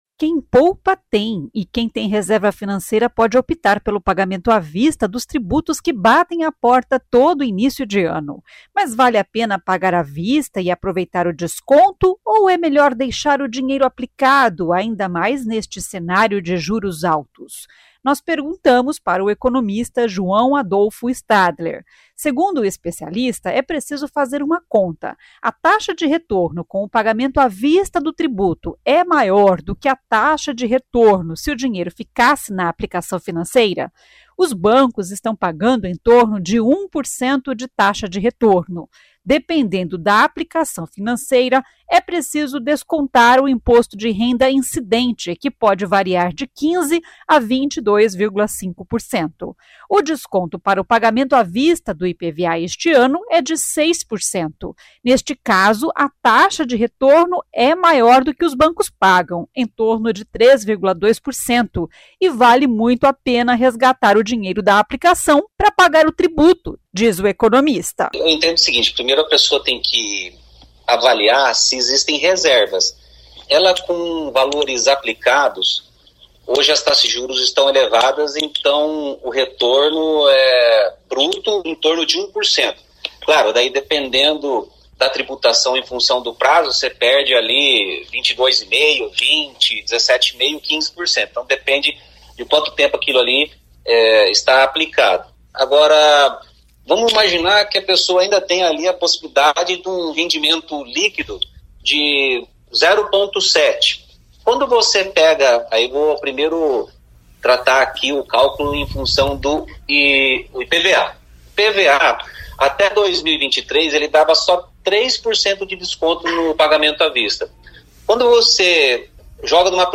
Economista ouvido pela CBN explica que a resposta depende da taxa de retorno com o desconto oferecido em cada tributo para o pagamento à vista.